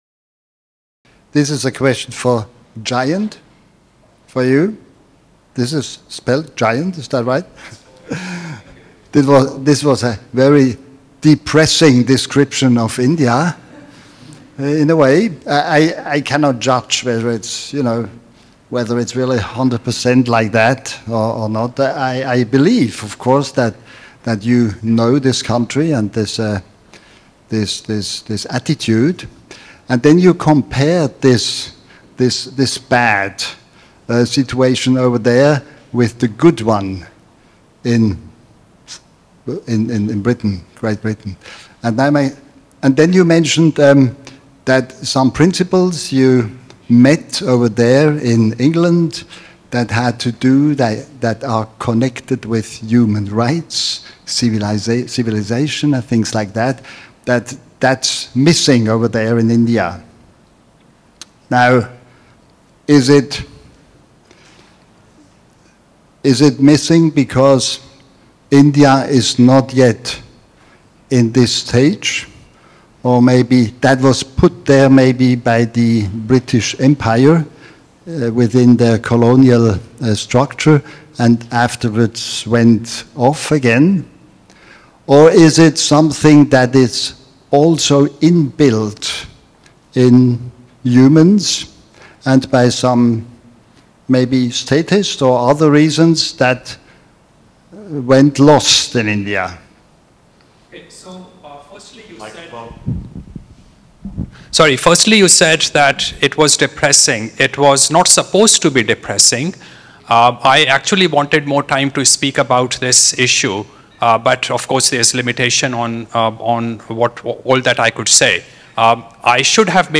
This panel discussion is from Day 1 of the recently-concluded 18th annual 2024 Annual Meeting of the PFS (Sept. 19–24, 2024, Bodrum, Turkey).